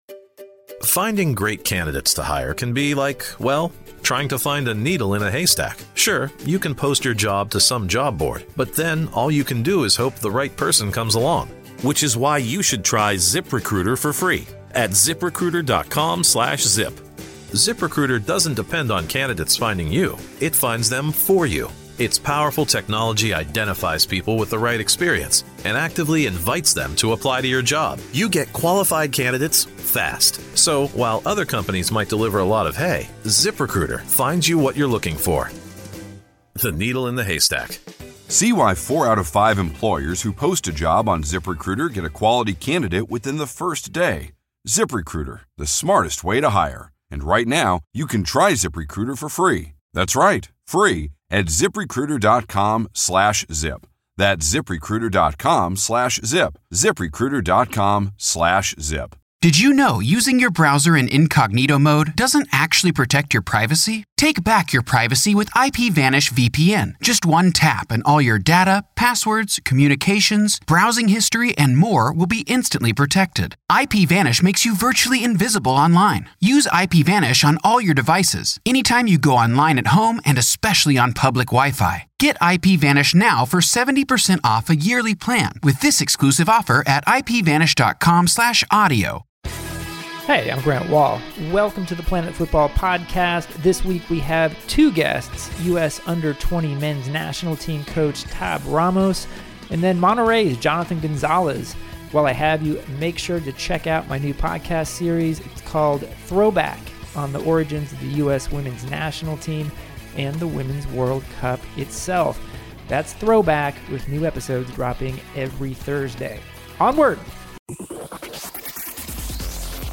Grant has two interviews this week. One is with Tab Ramos about his U.S. team at the upcoming Under-20 World Cup and his future as a coach. The second is with Monterrey’s Jonathan González about his team’s recent CONCACAF Champions League title and his journey with Sueño Alianza.